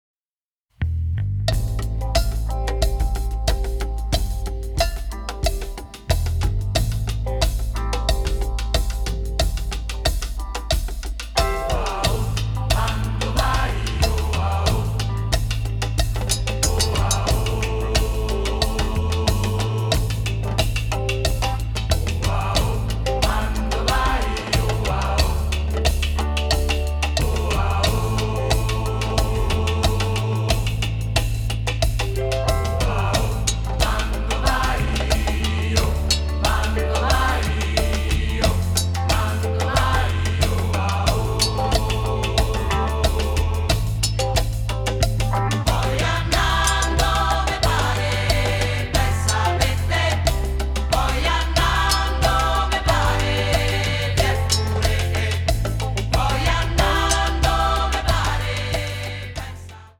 alt. vocal